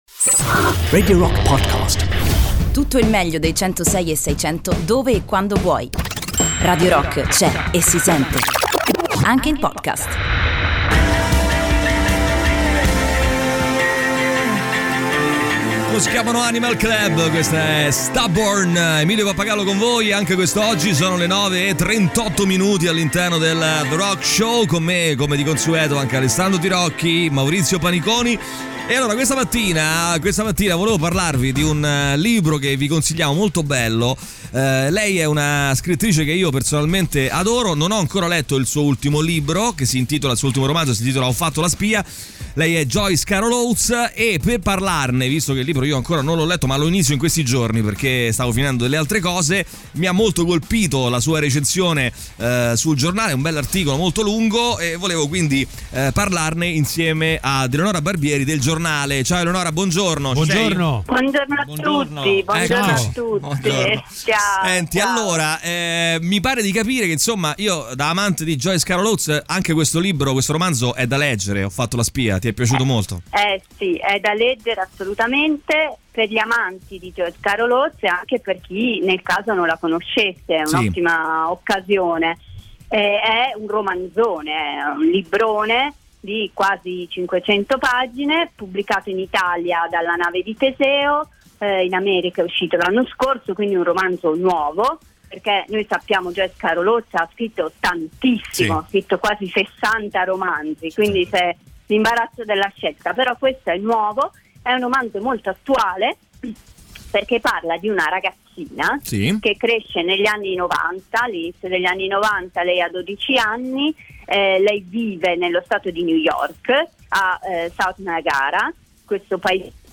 Intervista
in collegamento telefonico